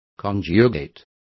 Complete with pronunciation of the translation of conjugate.